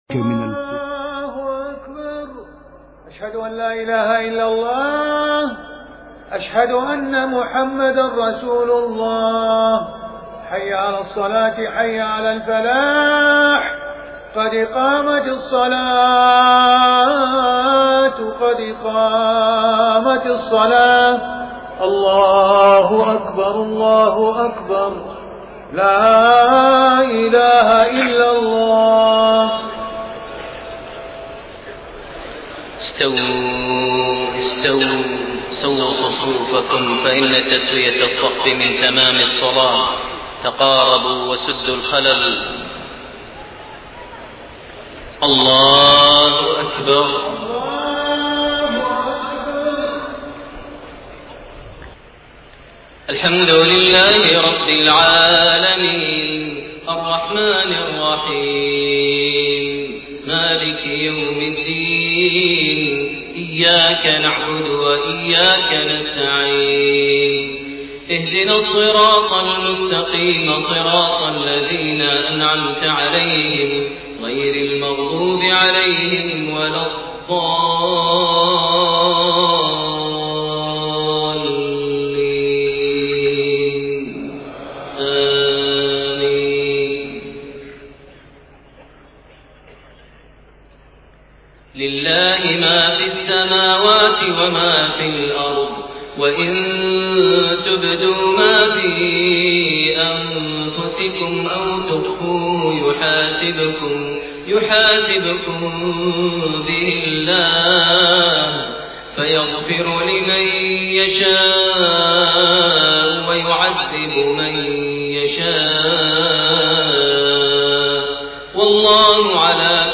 Maghrib prayer from Surat Al-Baqara > 1428 H > Prayers - Maher Almuaiqly Recitations